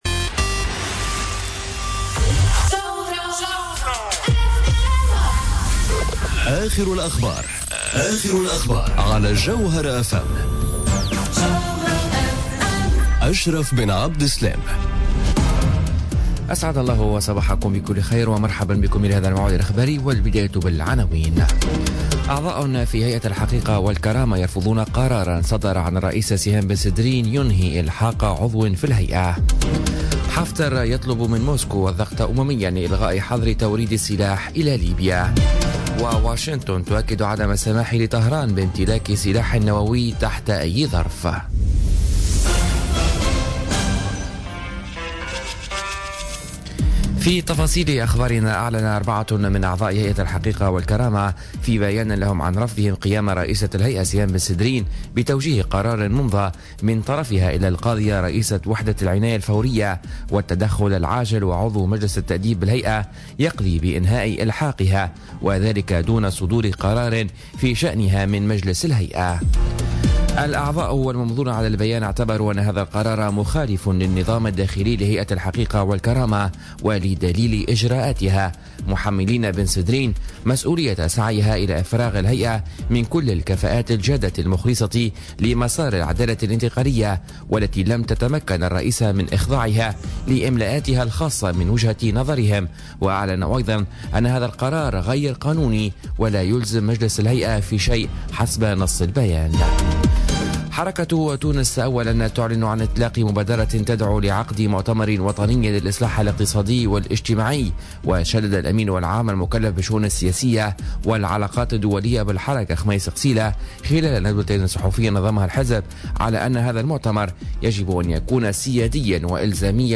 نشرة أخبار السابعة صباحا ليوم الإربعاء 16 أوت 2017